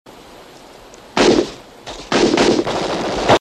MACHINE GUNS DISCONTINUED FIRE.mp3
Original creative-commons licensed sounds for DJ's and music producers, recorded with high quality studio microphones.
machine_guns_discontinued_fire_1wo.ogg